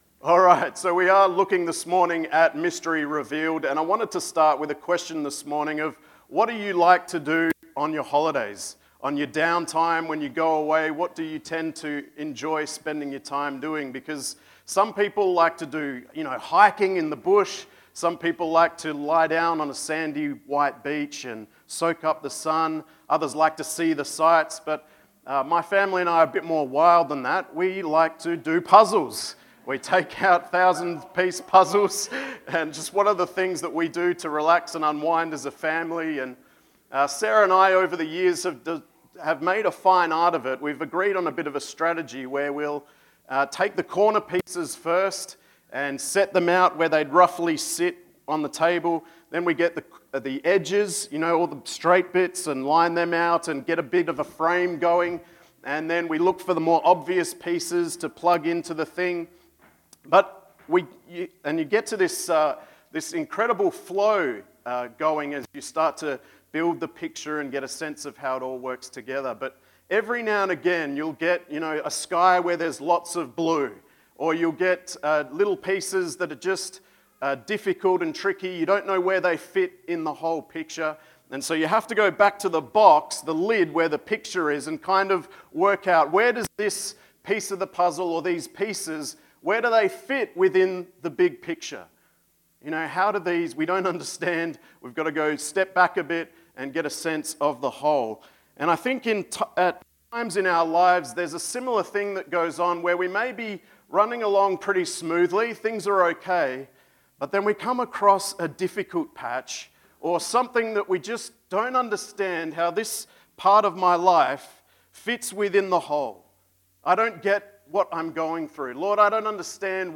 Sermons | Coolbellup Community Church